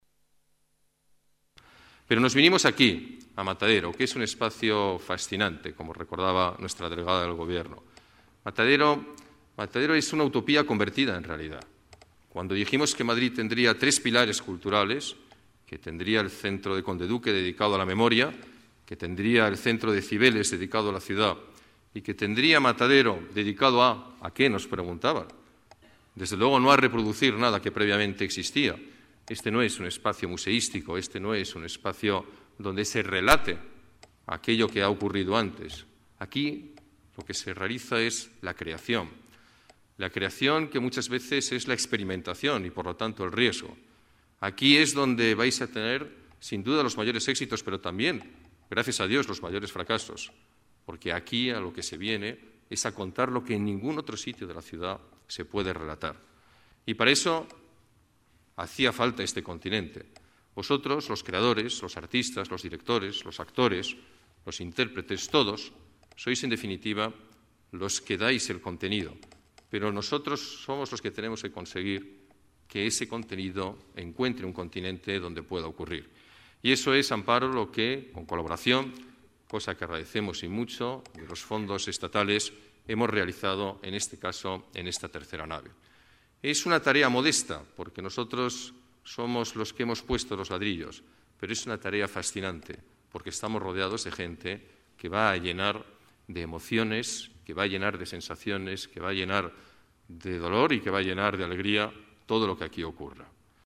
Nueva ventana:Declaraciones del alcalde de la Ciudad de Madrid, Alberto Ruiz-Gallardón: Nueva Nave Matadero Madrid